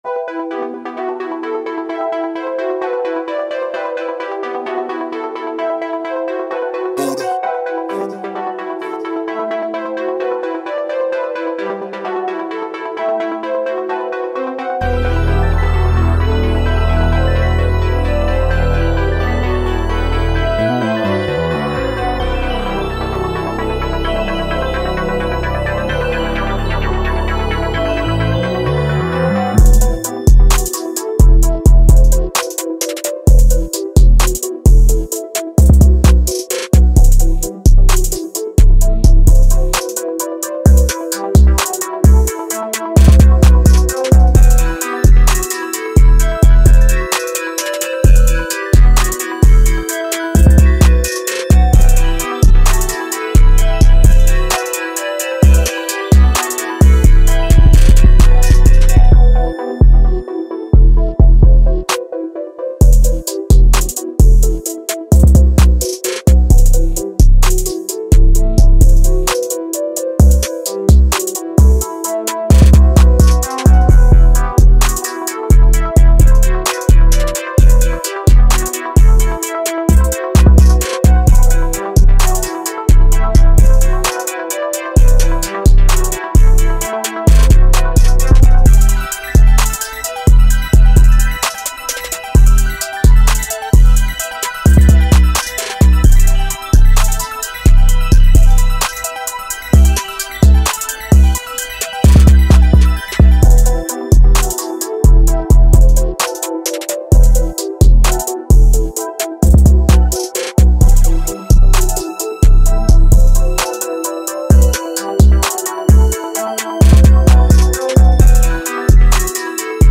with its intense and dynamic rhythms.